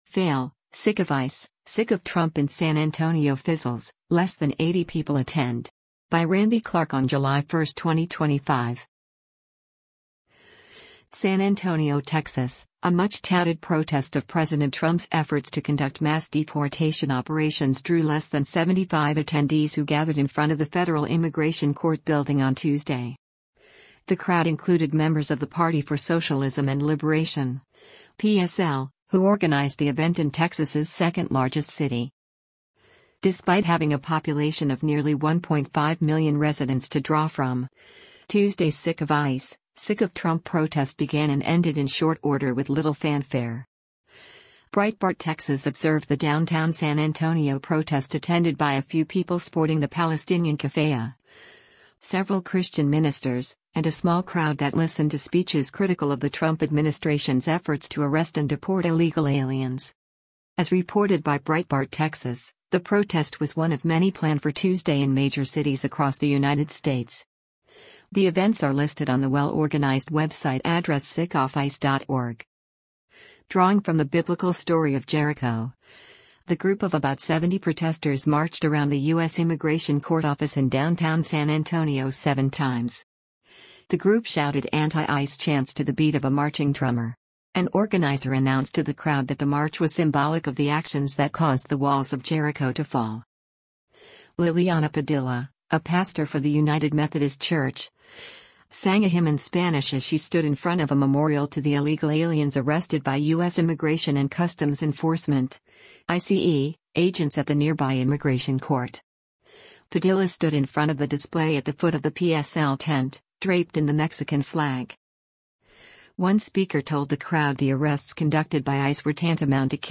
The group shouted anti-ICE chants to the beat of a marching drummer.